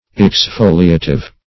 Search Result for " exfoliative" : The Collaborative International Dictionary of English v.0.48: Exfoliative \Ex*fo"li*a"tive\, a. [Cf.F. exfoliatif.]